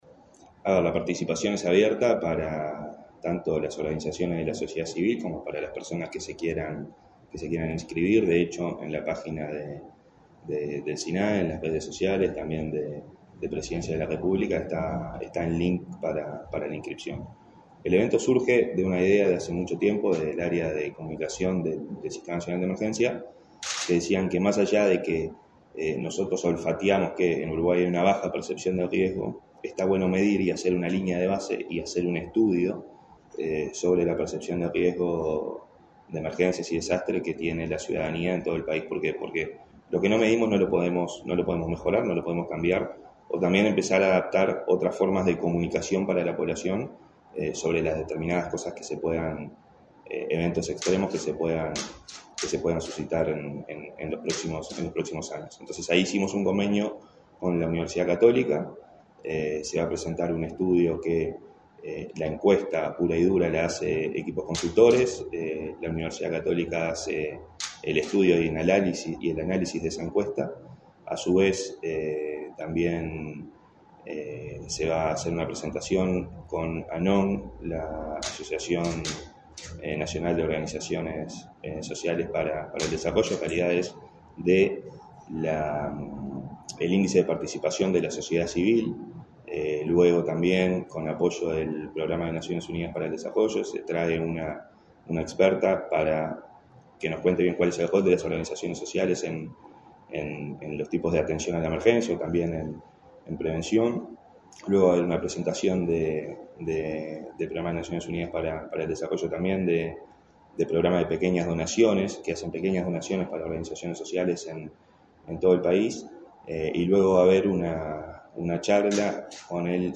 Entrevista al director del Sinae, Santiago Caramés